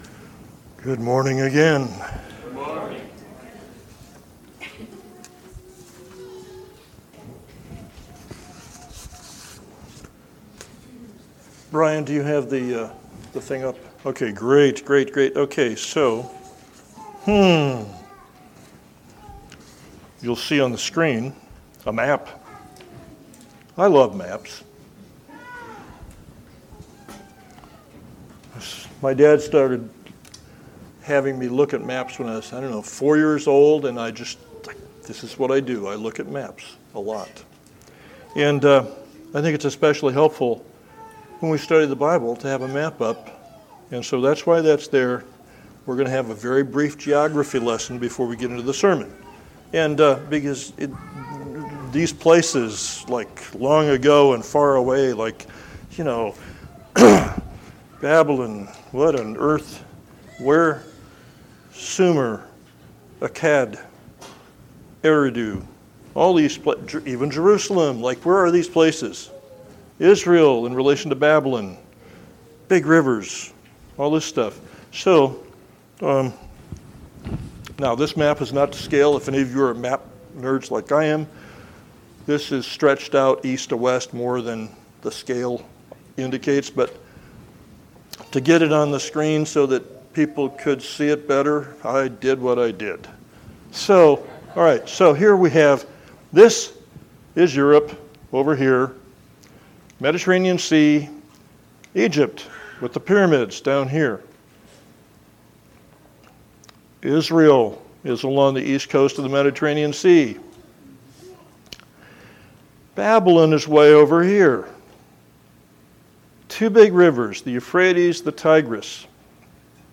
Sermon-6-23-24-Edit.mp3